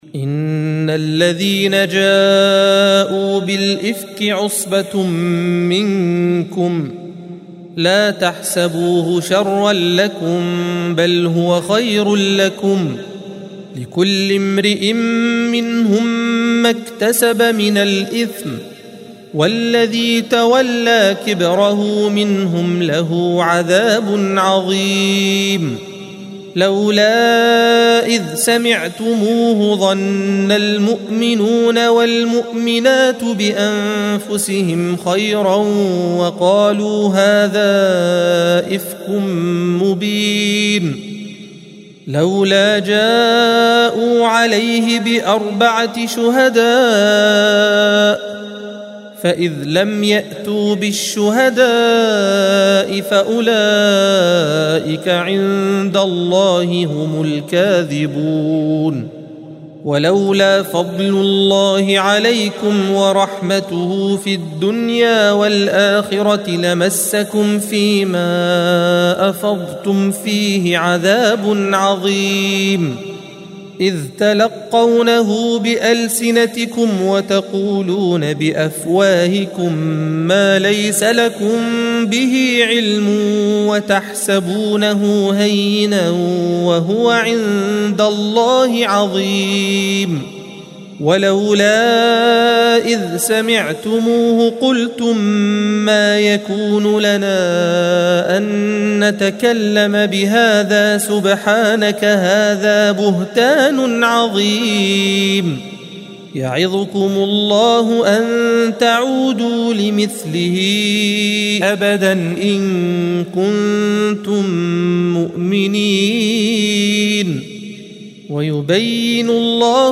الصفحة 351 - القارئ